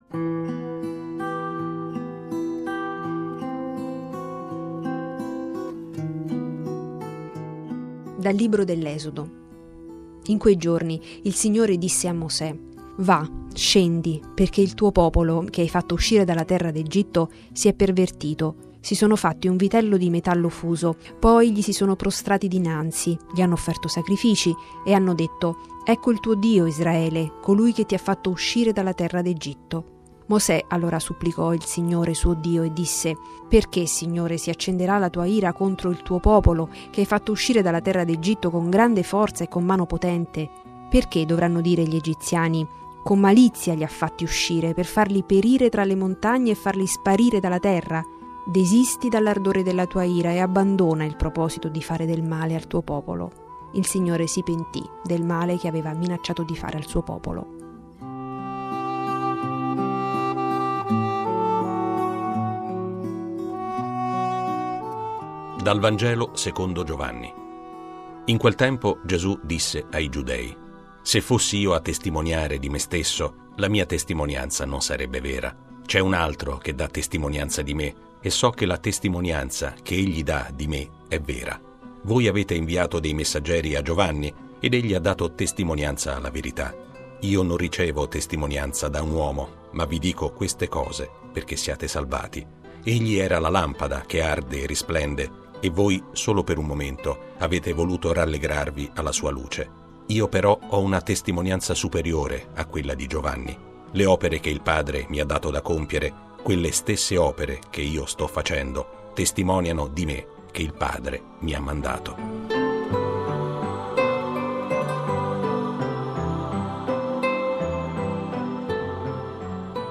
Le letture del giorno (prima e Vangelo) e le parole di Papa Francesco da VaticanNews: